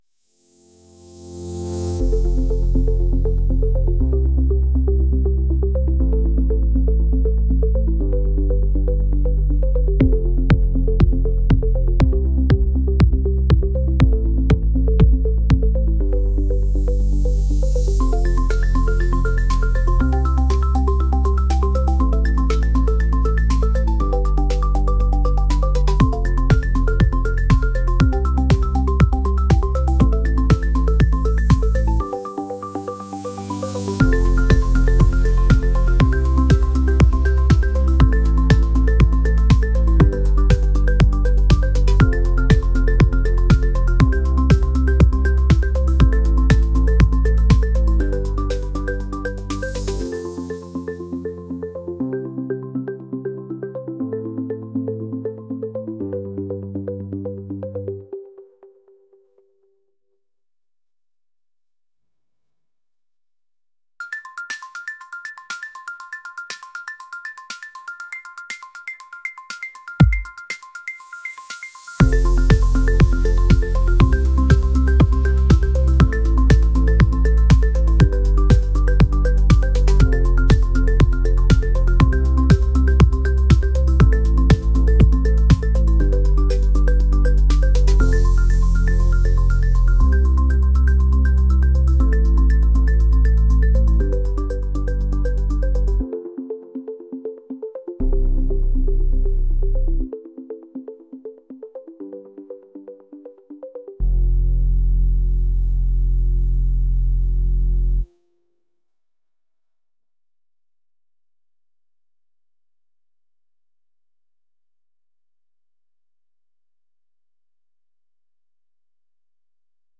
upbeat | electronic